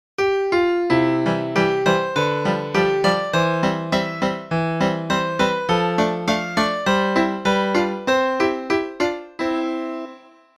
ノーリツ製の給湯器と同じ感じで作成してみました。
クラシック